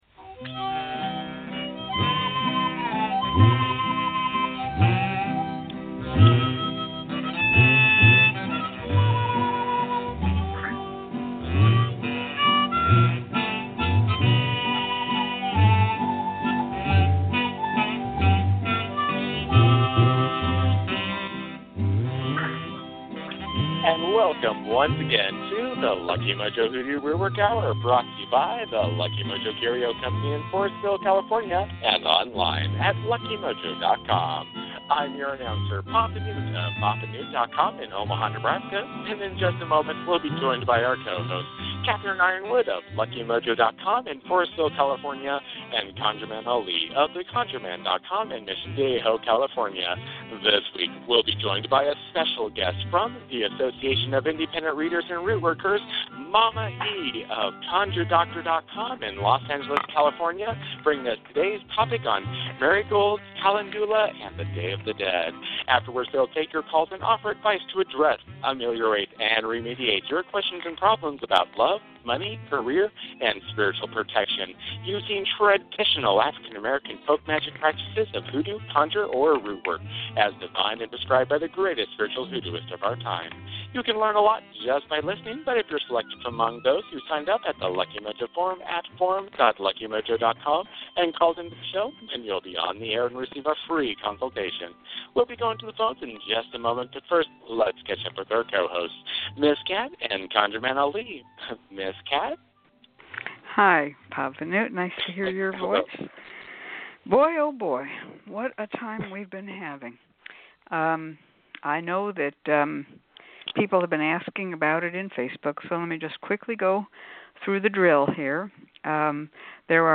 followed by free psychic readings, hoodoo spells, and conjure consultations, giving listeners an education in African-American folk magic.